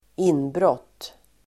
Uttal: [²'in:bråt:]